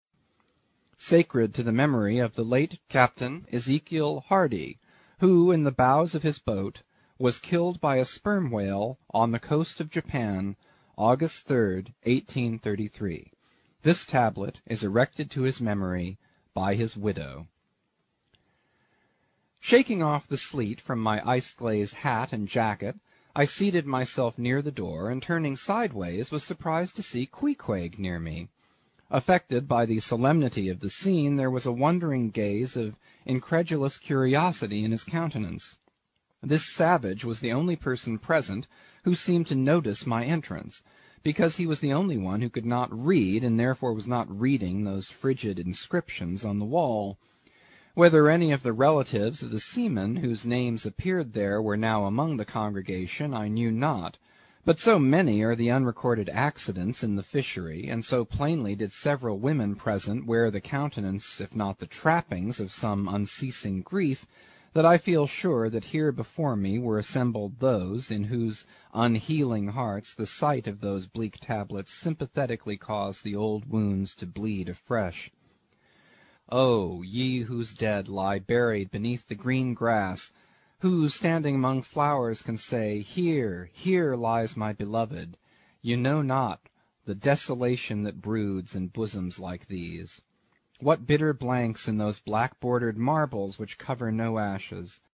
英语听书《白鲸记》第230期 听力文件下载—在线英语听力室